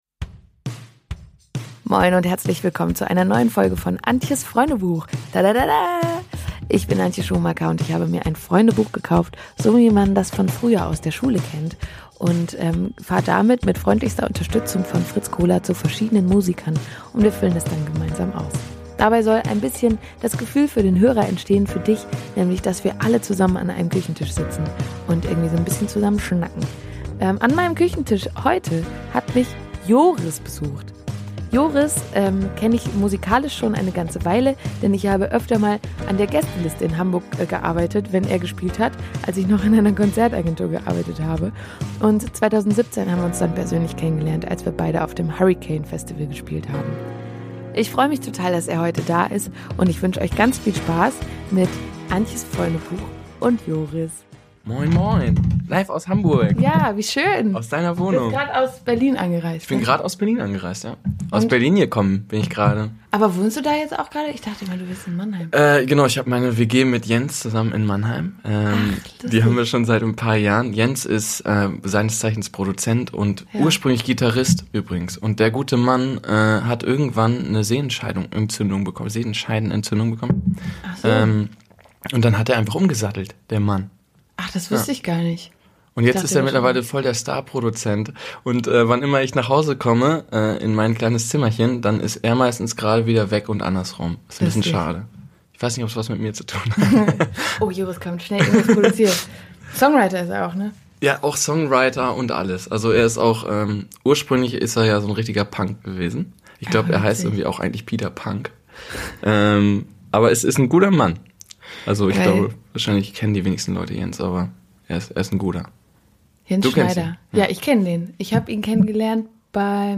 Folge 6JORIS hat mich in meiner Küche in Hamburg besucht ein paar Tage nach den Vorfällen inChemnitz. Wir hatten ein sehr nachdenkliches und auch aufreibendes Gespräch über die Gedanken, dieman sich derzeit über die politische Lage macht und die Aufg...